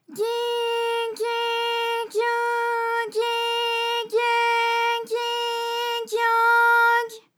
ALYS-DB-001-JPN - First Japanese UTAU vocal library of ALYS.
gyi_gyi_gyu_gyi_gye_gyi_gyo_gy.wav